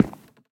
Minecraft Version Minecraft Version latest Latest Release | Latest Snapshot latest / assets / minecraft / sounds / block / cherry_wood / step4.ogg Compare With Compare With Latest Release | Latest Snapshot
step4.ogg